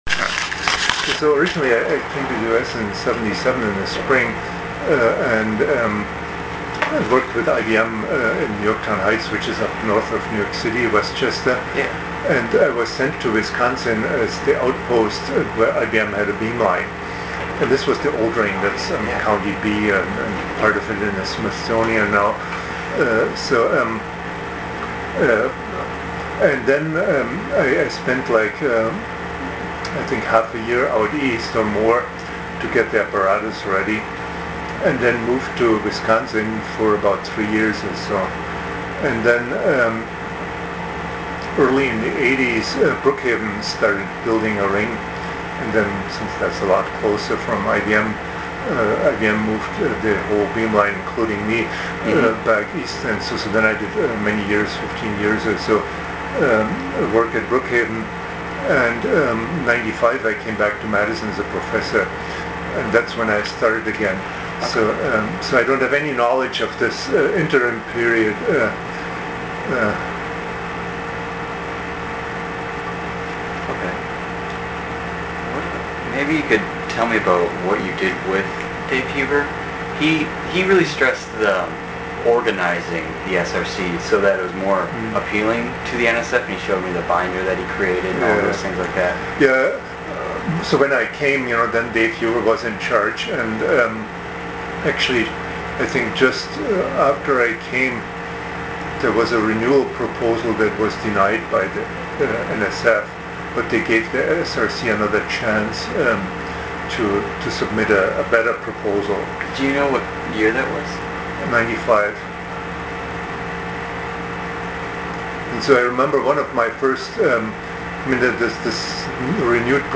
oral history
Oral history